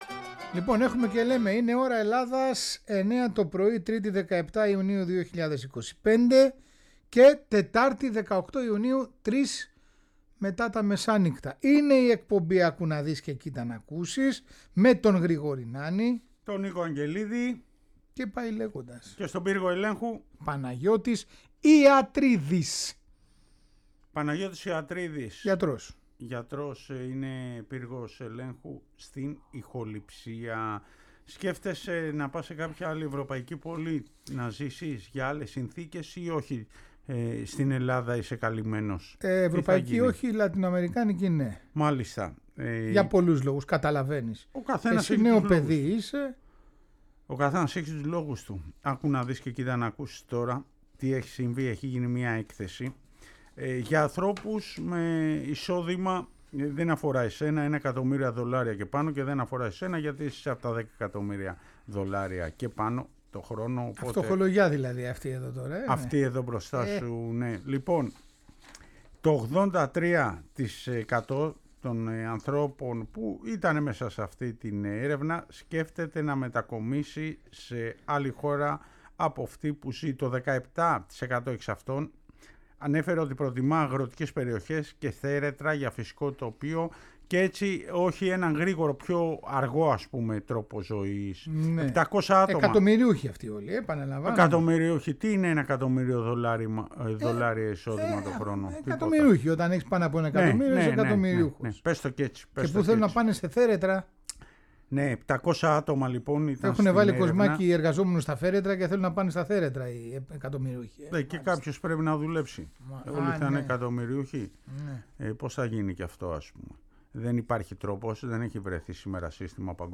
Τέλος μαθαίνουμε την ιστορία του πρωταθλητή Παννεμεατικού και ακούμε τον φοβερό ύμνο της ομάδας…